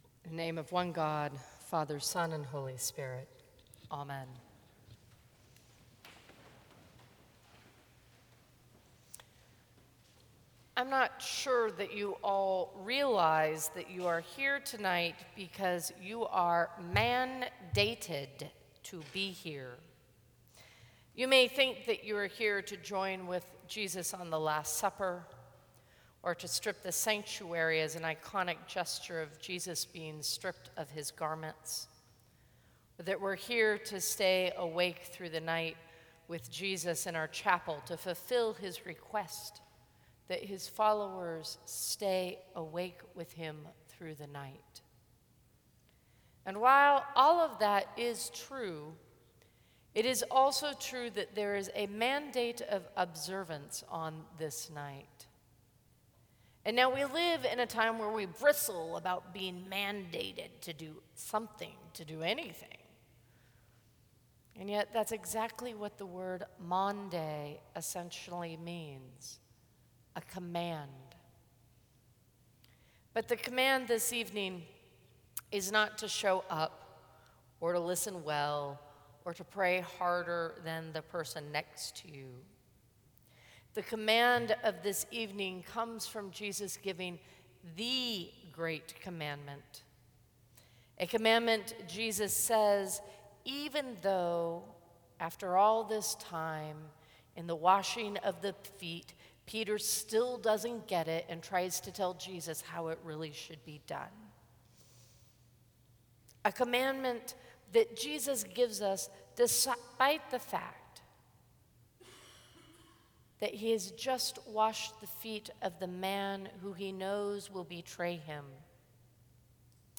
Sermons from St. Cross Episcopal Church
Sunday Sermon